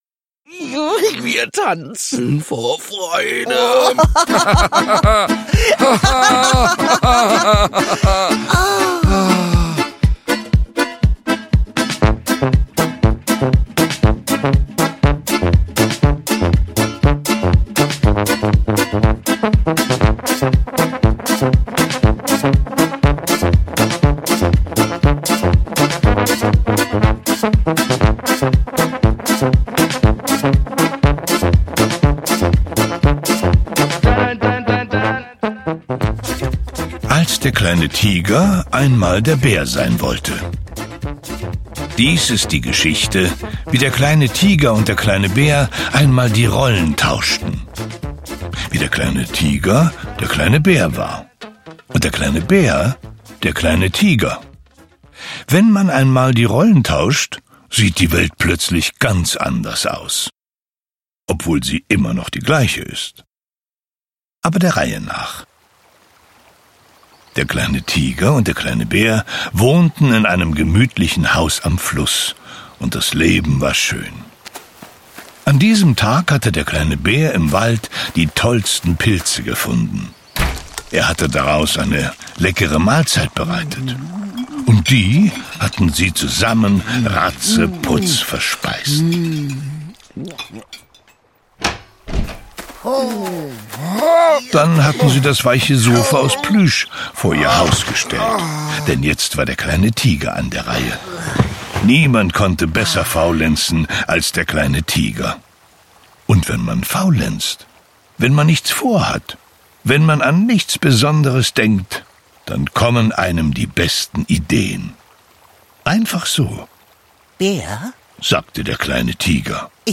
Hörbuch: Nach einer Figurenwelt von Janosch.